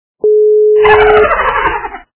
» Звуки » Смешные » Мышонок - Смех
При прослушивании Мышонок - Смех качество понижено и присутствуют гудки.
Звук Мышонок - Смех